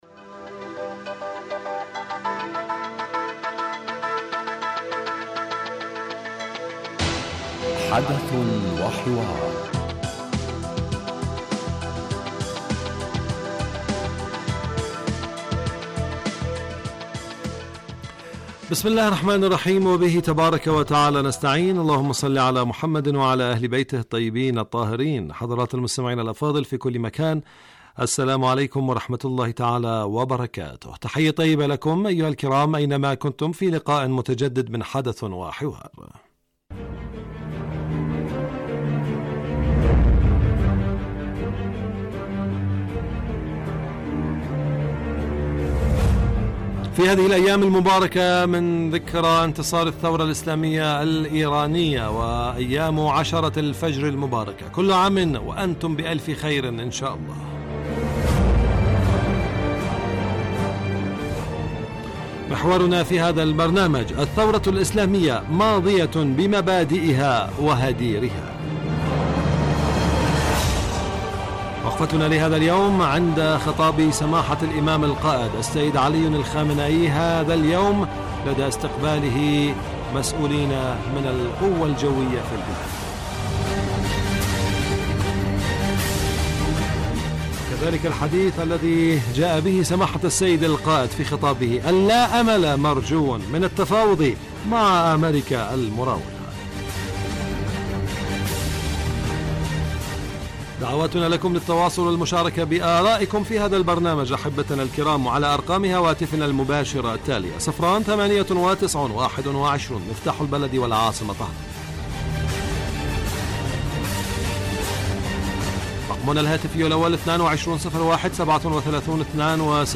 يبدأ البرنامج بمقدمة يتناول فيها المقدم الموضوع ثم يطرحه للنقاش من خلال تساؤلات يوجهها للخبير السياسي الضيف في الاستوديو . ثم يتم تلقي مداخلات من المستمعين هاتفيا حول الرؤى التي يطرحها ضيف الاستوديو وخبير آخر يتم استقباله عبر الهاتف ويتناول الموضوع بصورة تحليلية.